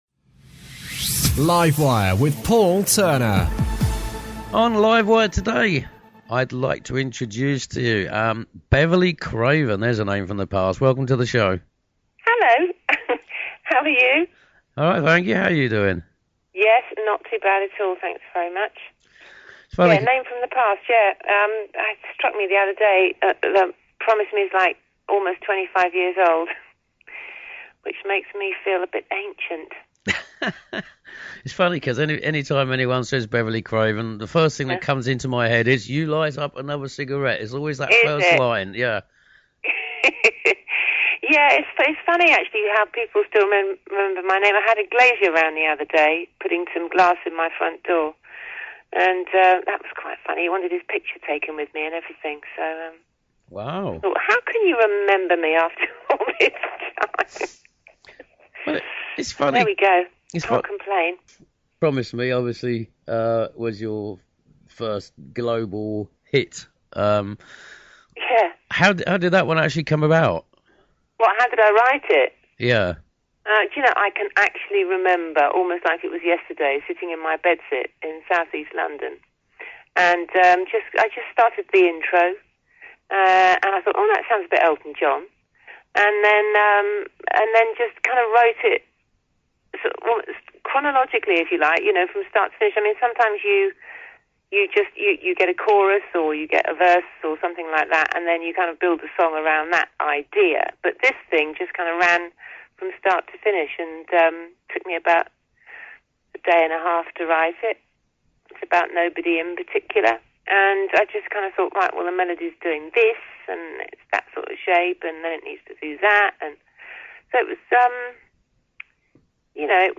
Beverly Craven Chats To Live Wire